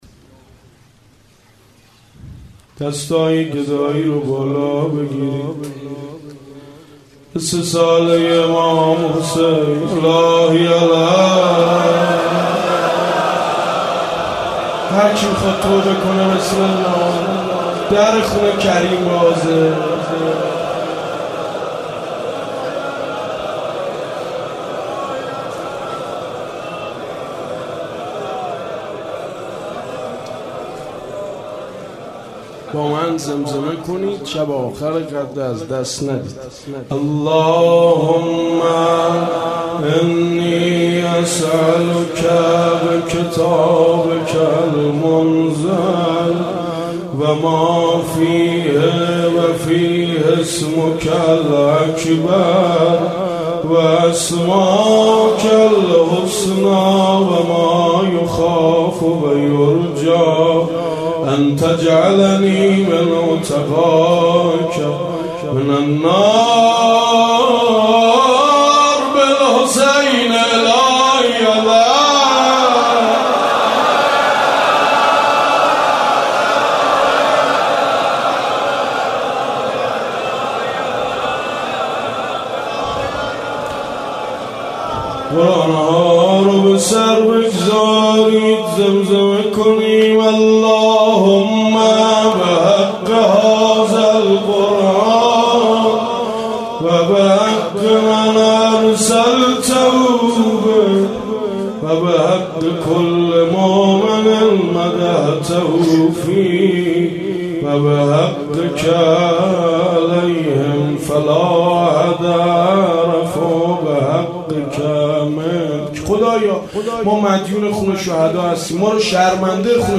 مناجات